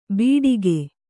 ♪ bīḍike